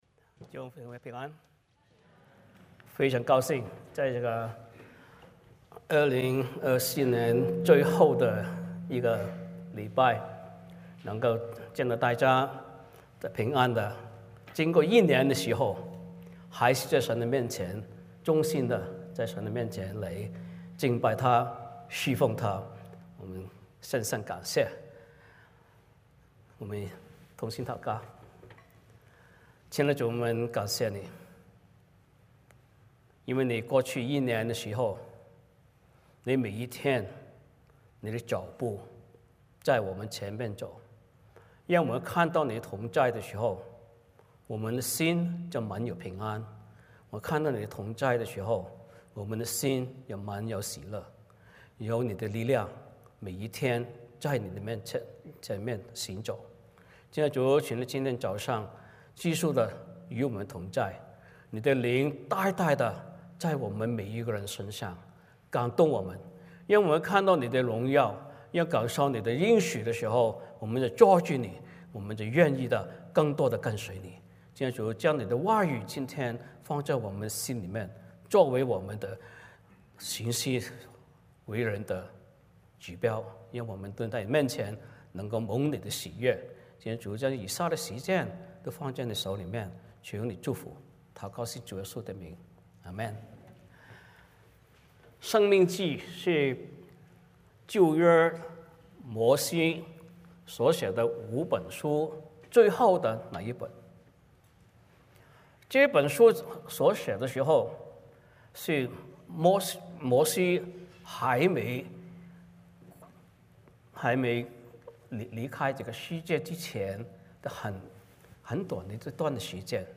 申命记 28:1-13 Service Type: 主日崇拜 欢迎大家加入我们的敬拜。